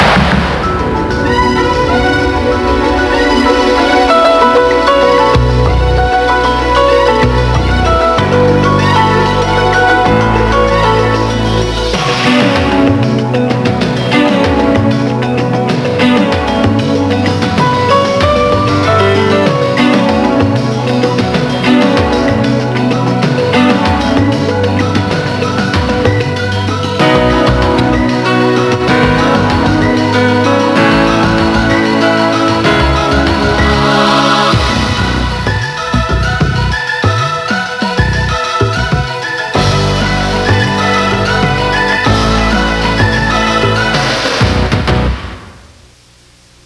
TV Themes